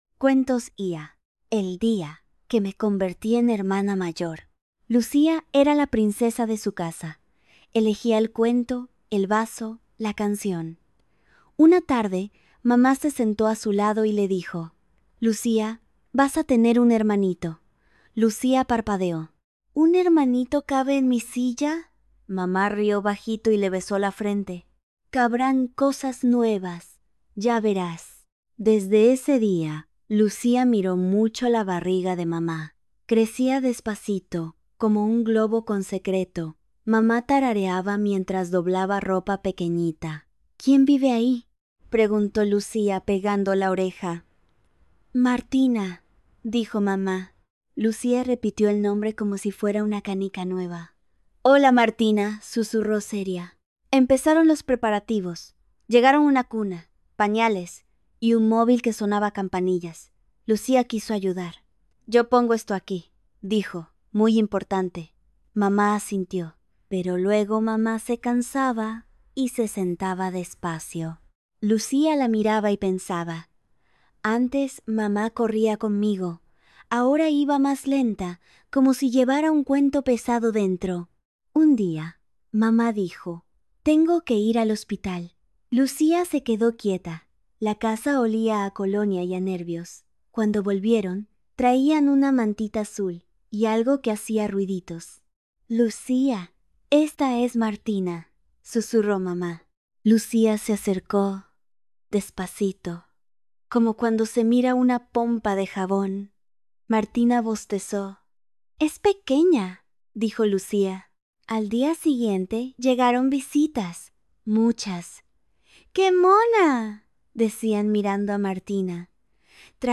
Audiolibro Narrado